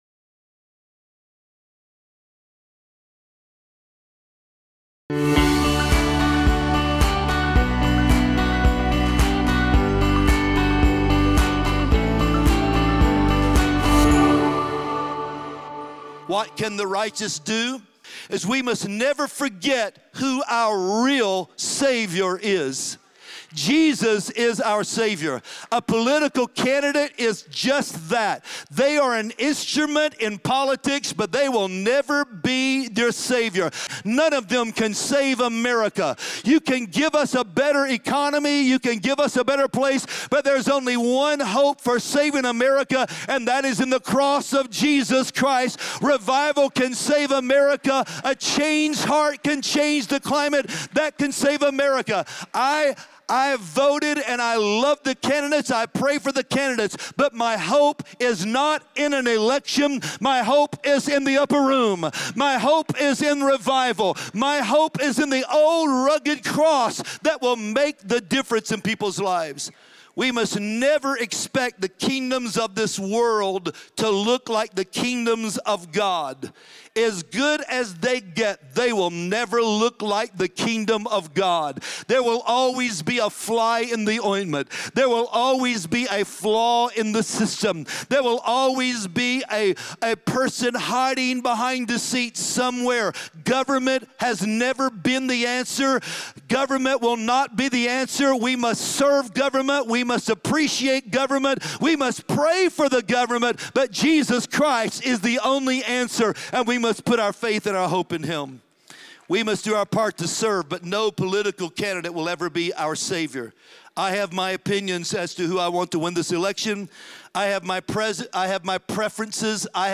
Join us this week for the sermon “Never Forget the Savior.”